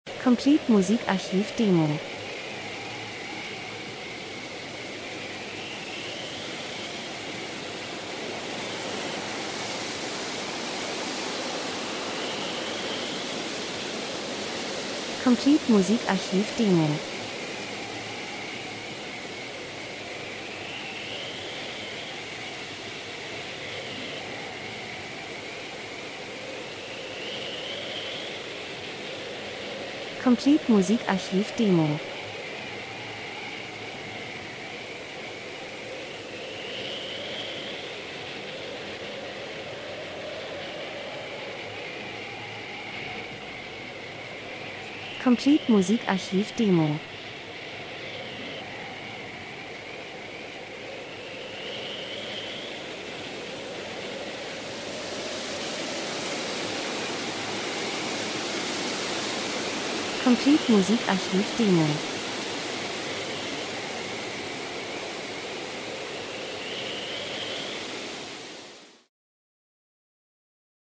Herbst -Geräusche Soundeffekt Herbststurm, Regen 01:10